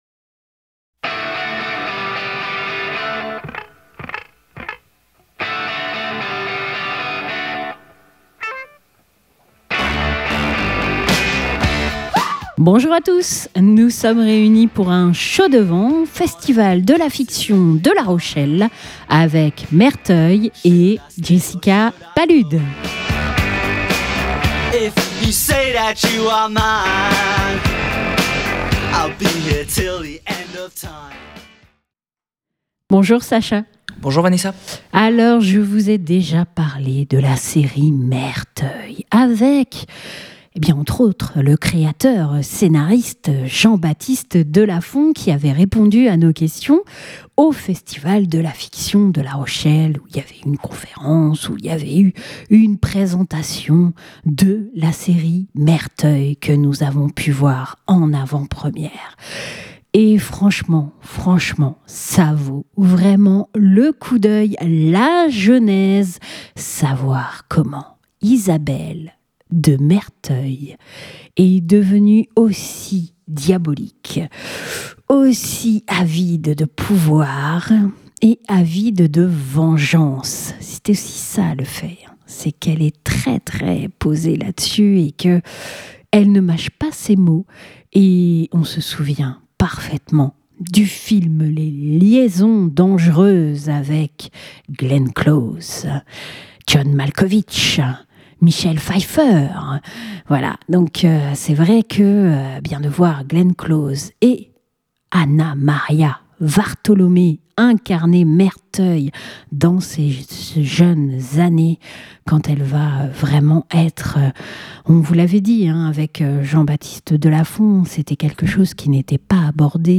La réalisatrice nous a d'ailleurs accordé une entrevue où elle revient sur l'aventure de "Merteuil" mais aussi sur le film "Maria" et sa rencontre avec Anamaria Vartolomei.